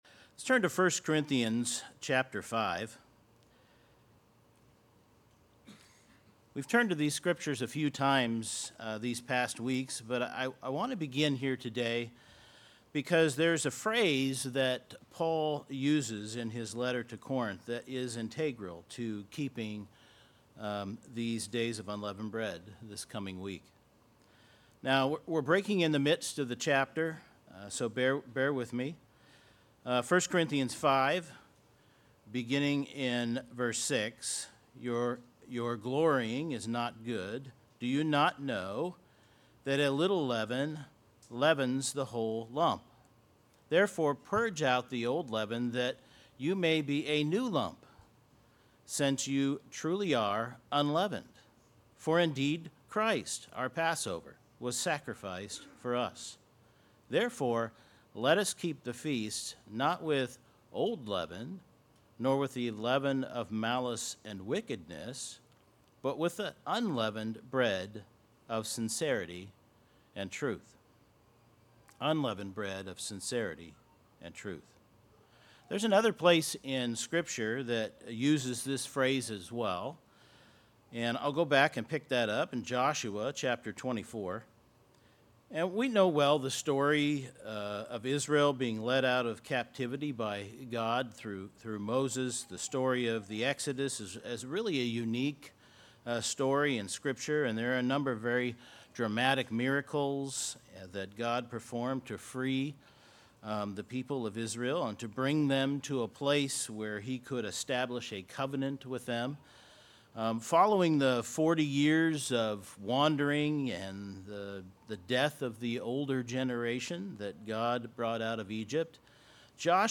Sermons
Given in Seattle, WA